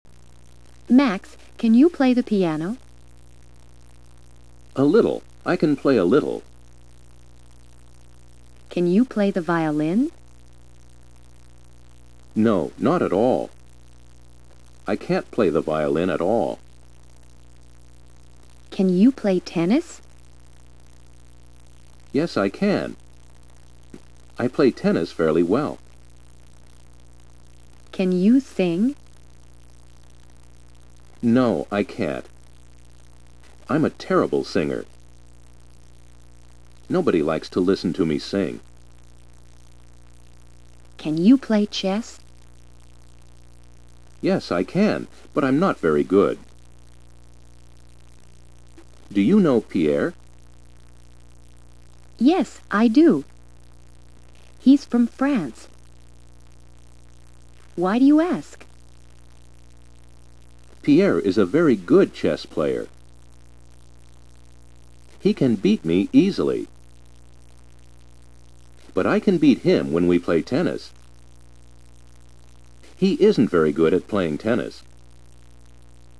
1.What day do man and woman try to meet ?